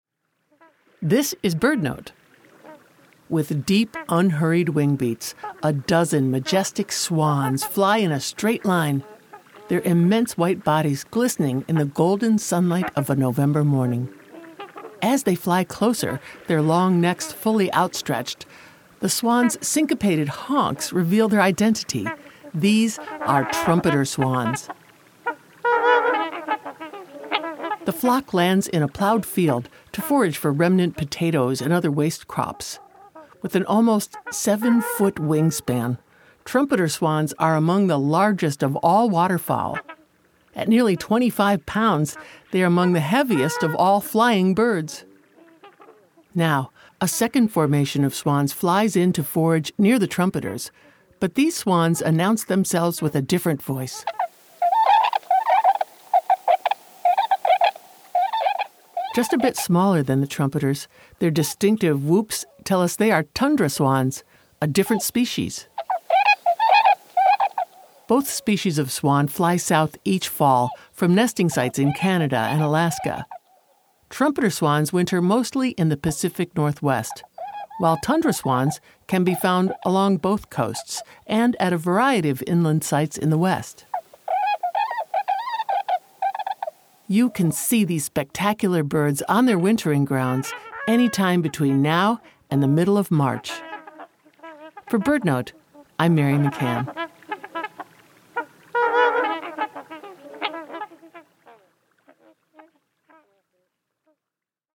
These swans migrate in family groups each fall from nesting sites in Canada and Alaska to wintering grounds in the central U.S. Trumpeter Swans are sometimes accompanied by their smaller cousins, the Tundra Swan. Both swan species look similar to each other, but their voices are distinct!